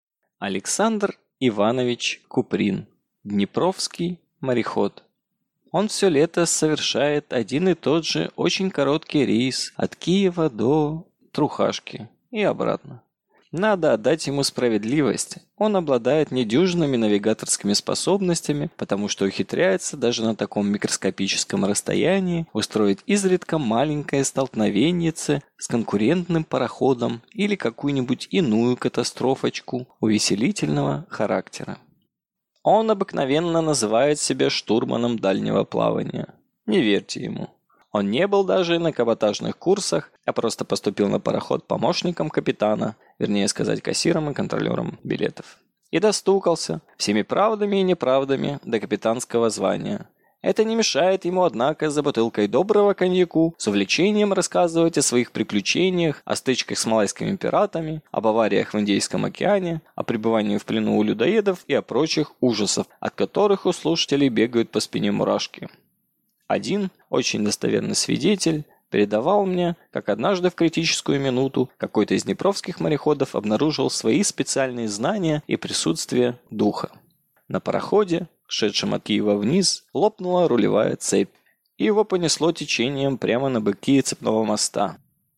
Аудиокнига Днепровский мореход | Библиотека аудиокниг